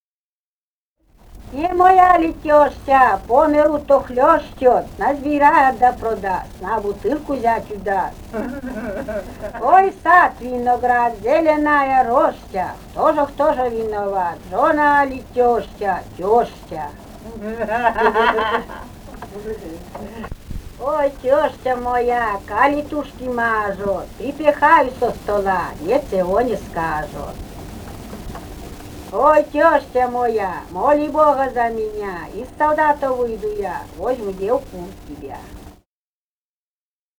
«Не моя ли тёща» (частушки).